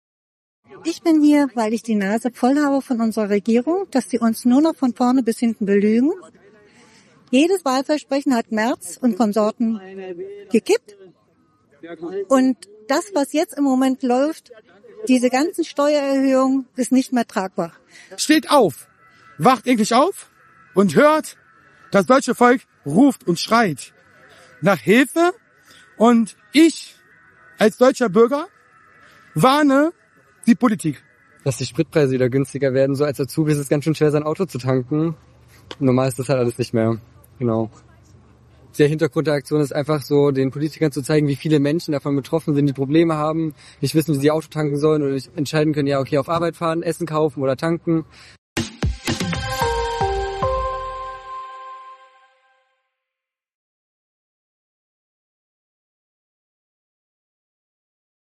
AUF1 hat die Stimmung vor Ort eingefangen.